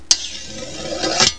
sharpen.mp3